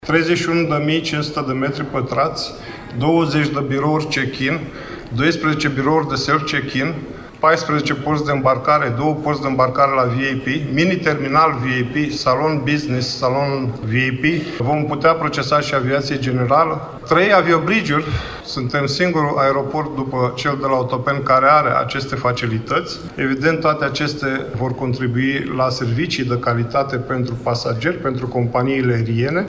La Iași, a avut loc astăzi recepția lucrărilor la terminalul 4 al Aeroportului Internațional, investiţie care a fost finalizată la sfârşitul lunii decembrie 2023.